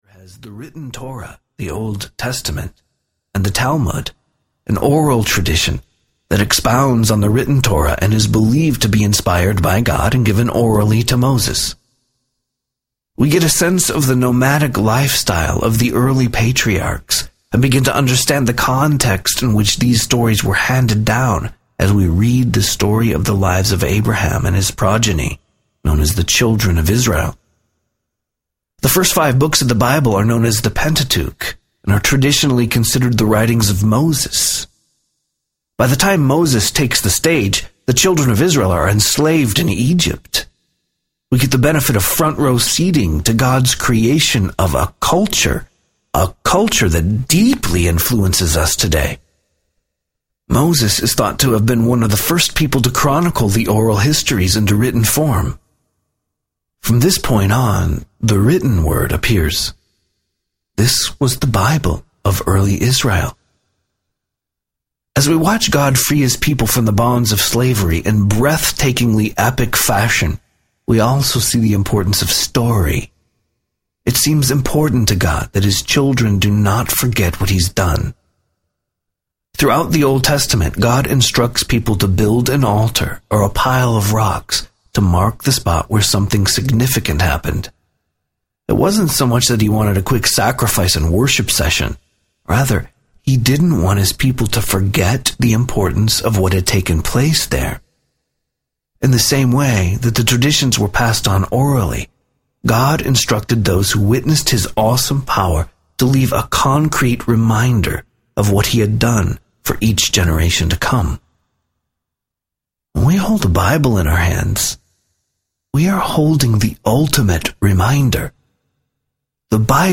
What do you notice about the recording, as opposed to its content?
4.75 Hrs. – Unabridged